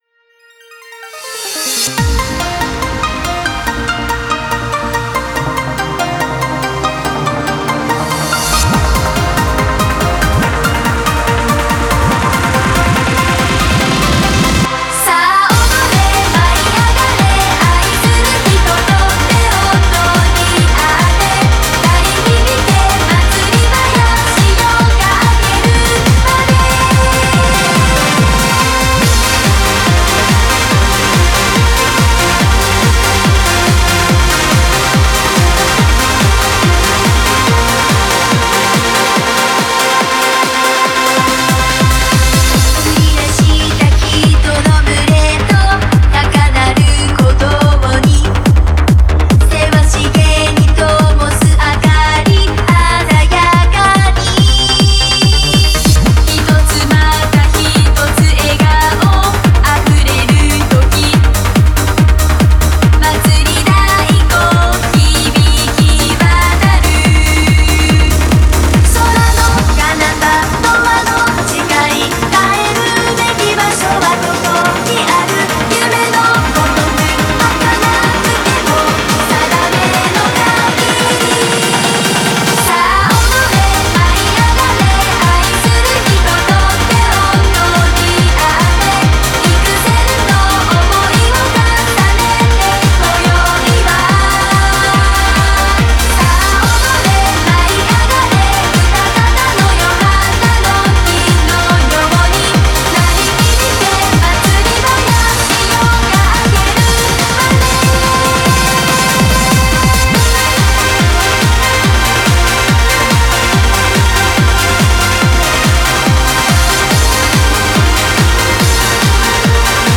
Genre : Trance
BPM : 142 BPM
Release Type : Bootleg
Remix-Edit